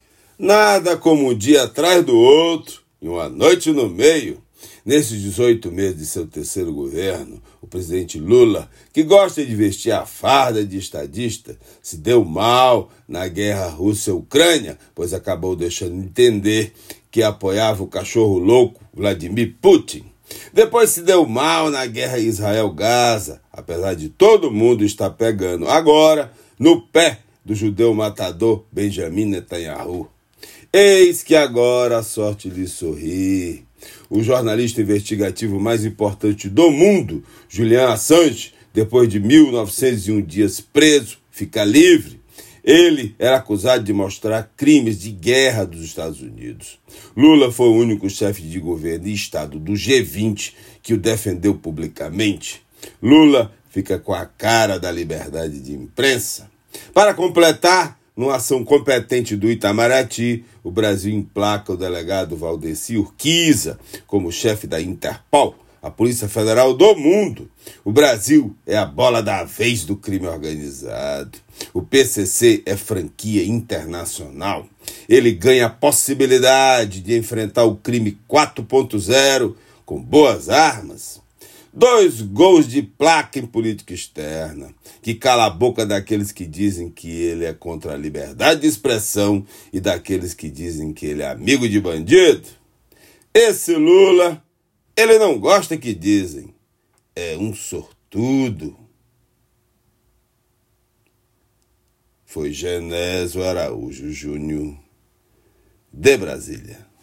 Comentário desta quarta-feira